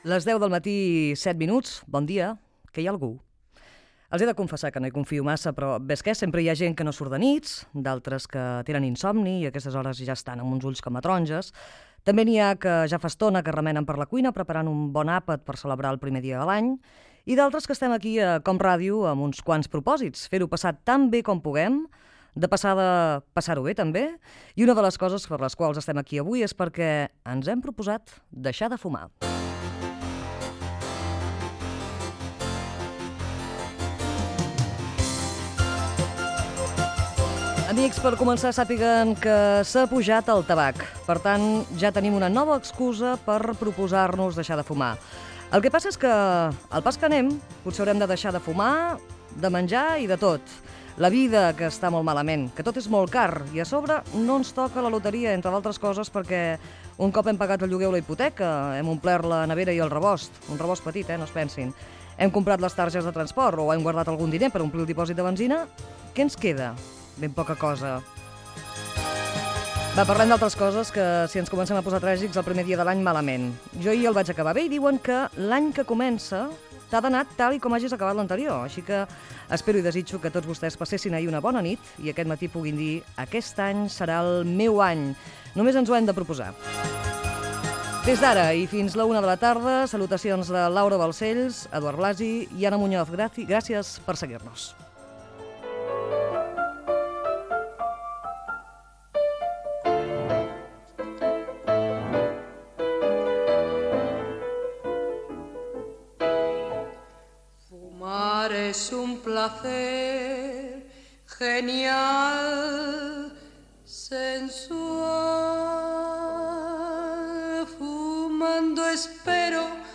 Programa emès el dia primer de l'any. Sumari i crèdits.
Entreteniment
Fragment extret de l'arxiu sonor de COM Ràdio